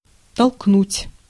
Ääntäminen
Ääntäminen US : IPA : [ˈɪnµ(ùtµ)ù.stə.ˌɡeɪt]